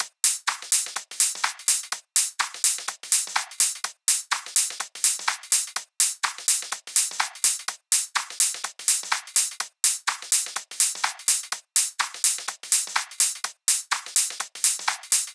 percus_fill_01.wav